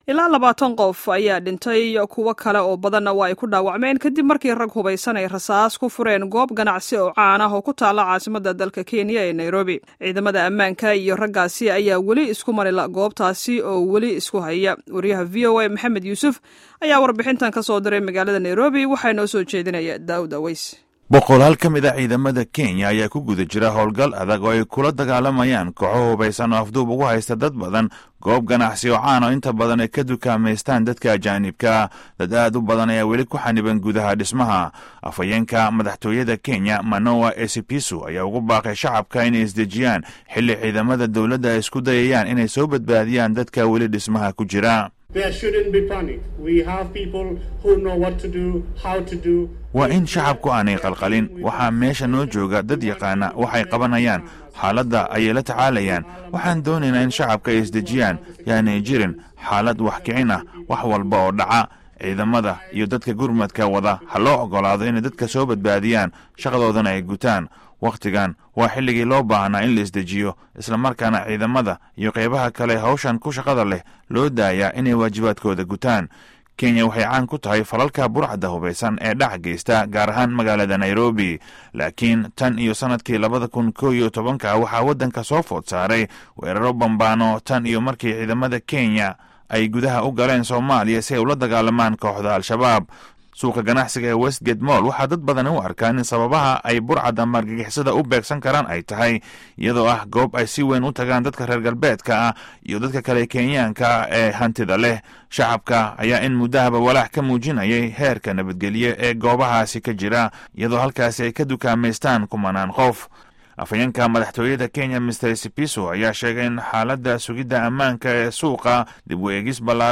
Dhageyso warbixinta Weerarka Nairobi